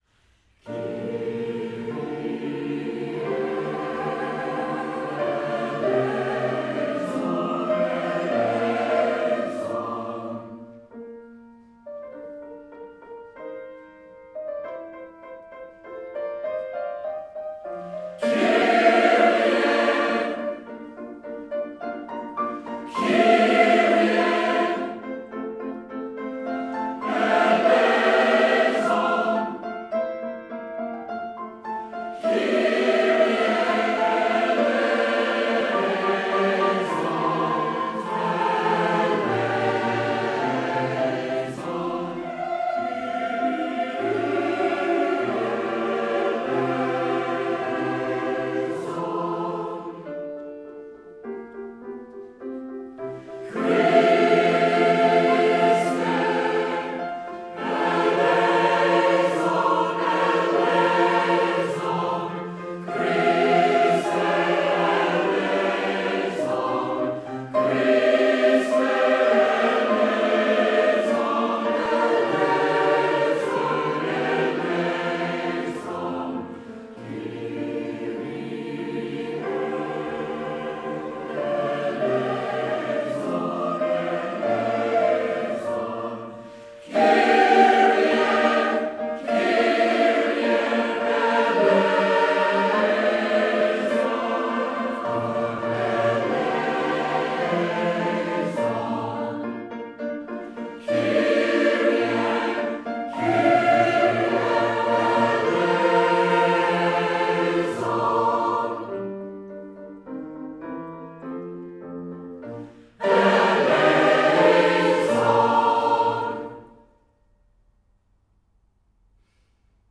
Recorded by individual members in their homes during lockdown